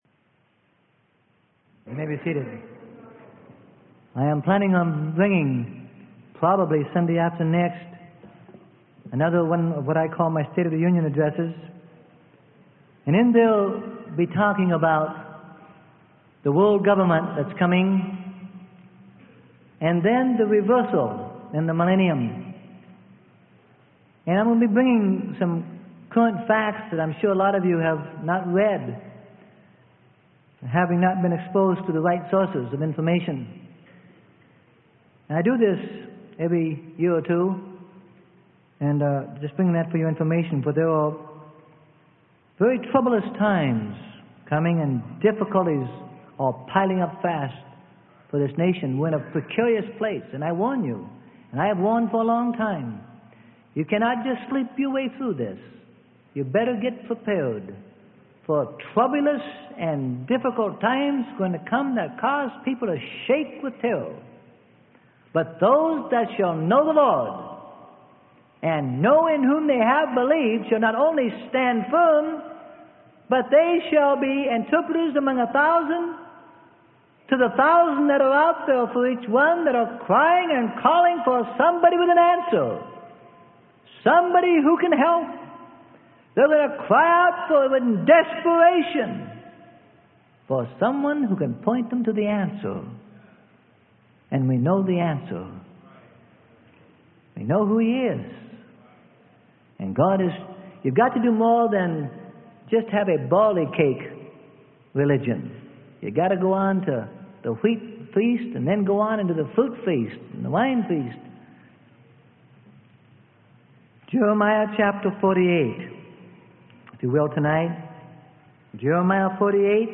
Sermon: The Vintage Wine Of God - Freely Given Online Library